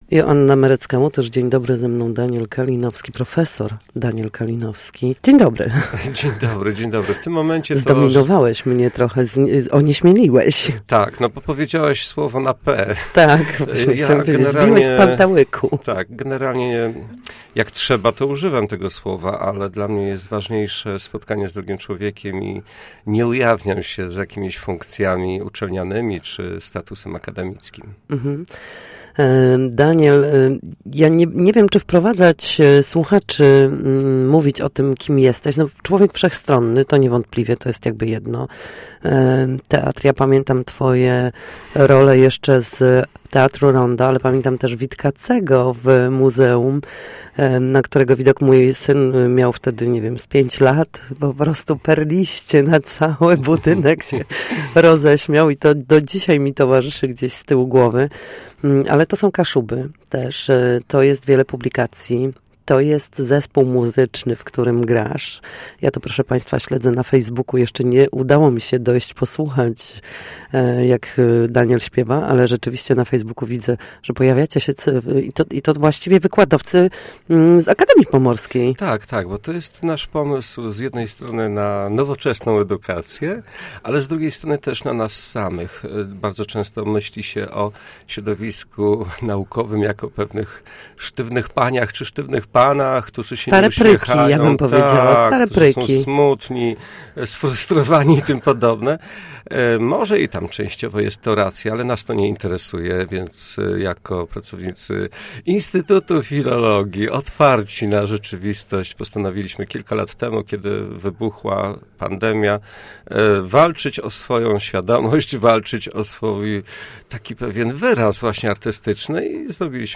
Zapraszam do wysłuchania rozmowy z wyjątkowym człowiekiem o teatrze na Kaszubach i nie tylko, posłuchaj: